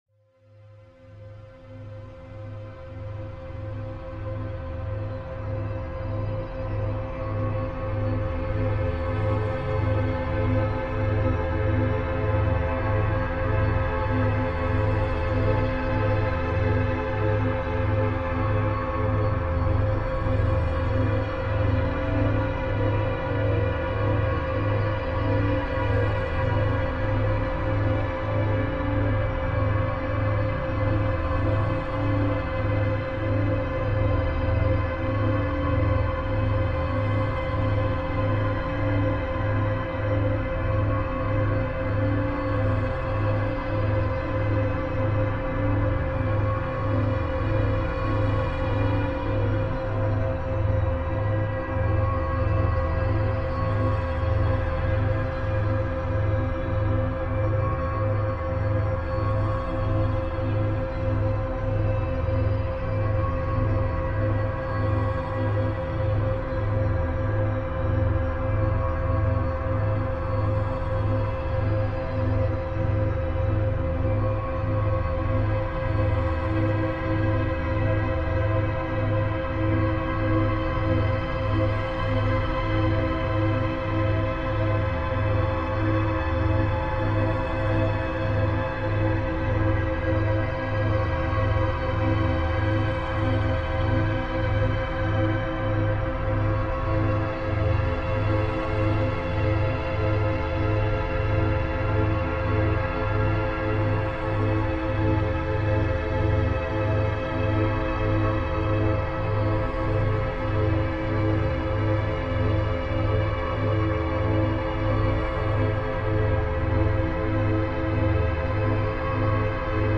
Golden Ratio Meditation – 1.618 Hz Phi Frequency Sound Healing